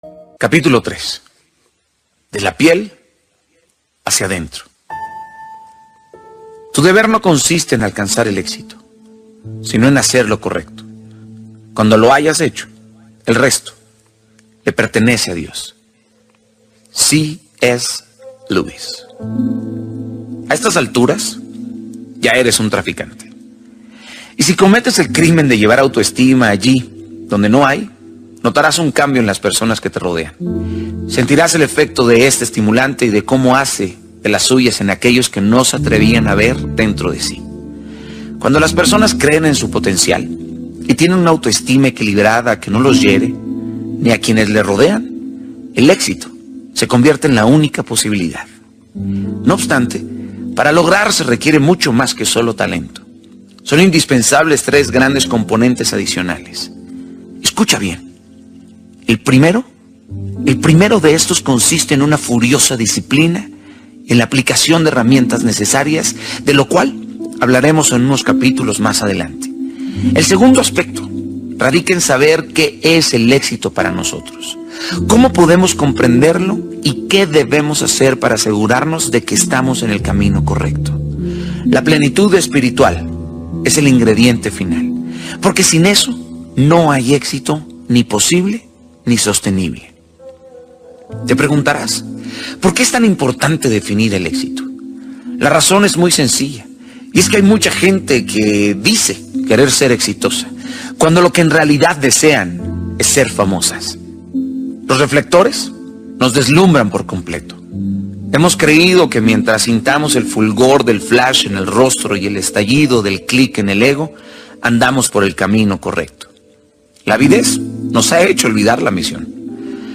Audiolibros